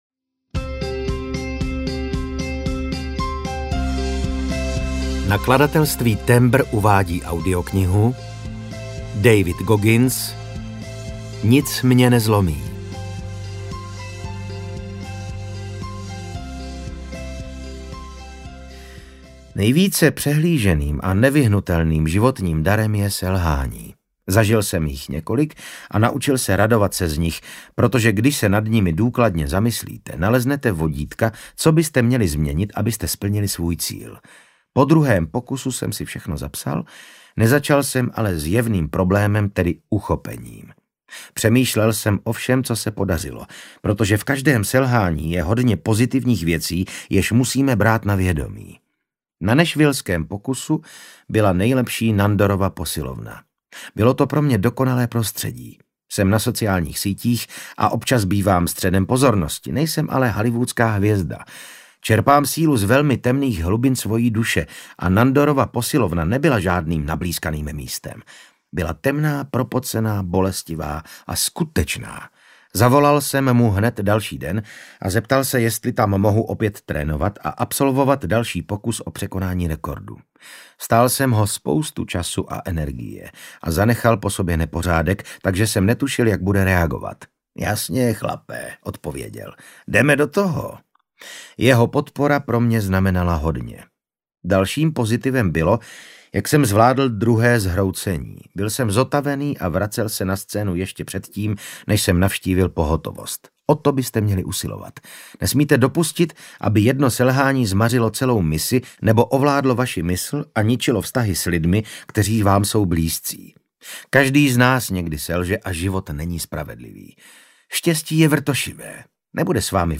Nic mě nezlomí audiokniha
Ukázka z knihy
• InterpretVasil Fridrich